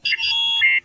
computer1.ogg